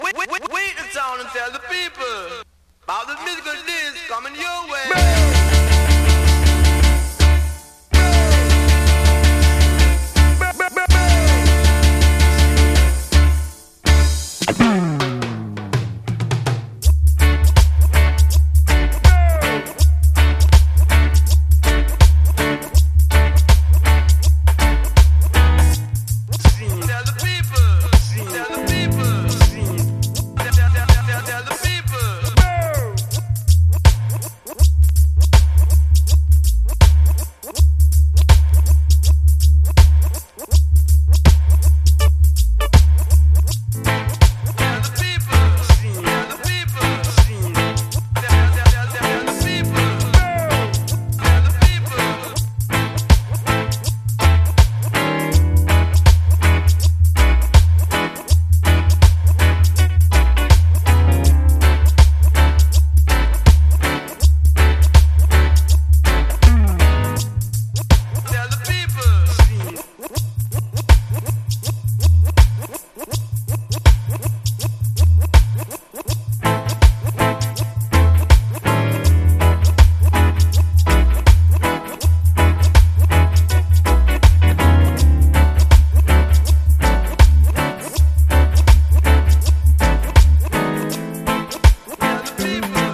レゲエやダンスホール界のみならず、ヒップホップの現場でもプライされまくったビッグ・チューン。